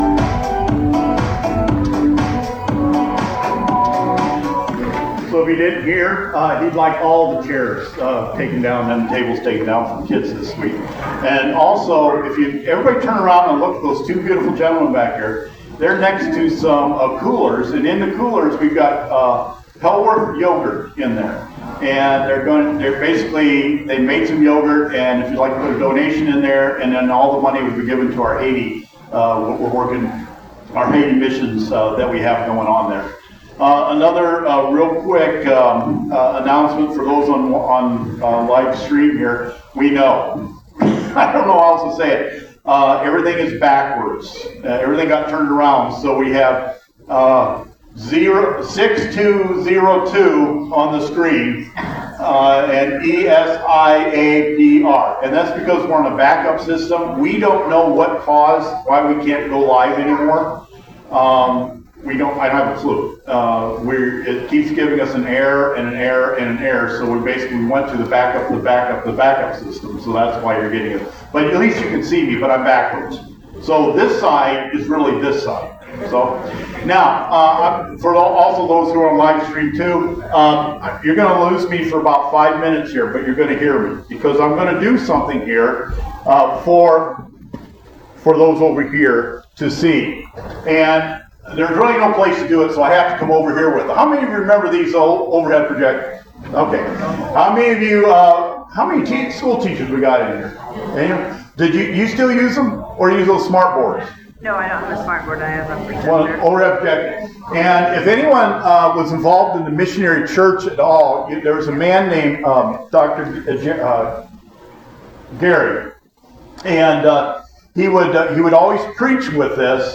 Sermons – Mount Tabor Church of God, Celina, Ohio